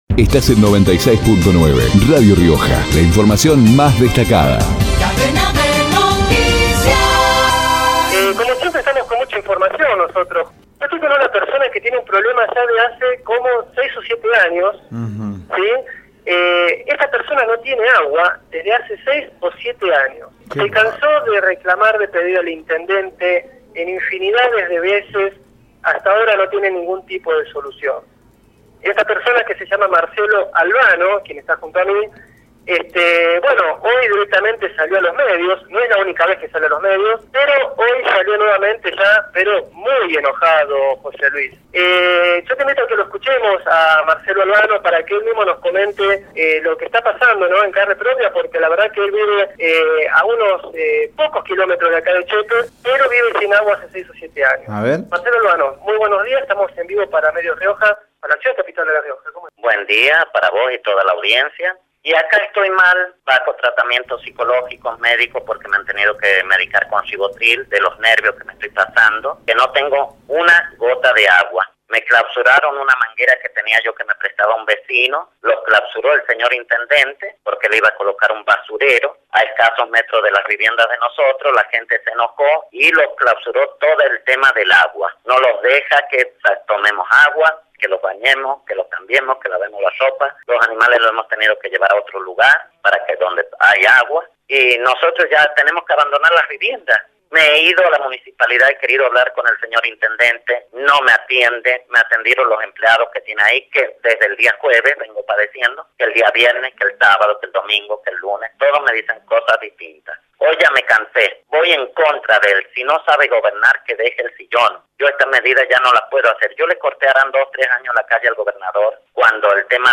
Vecino de Chepes por Radio Rioja Rubén Díaz, ex concejal de Chamical, por Radio Rioja Vecinos autoconvocados de Chamical por Radio Rioja Enrique Nicolini, diputado, por Radio Rioja